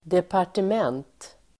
Uttal: [depar_tem'en:t]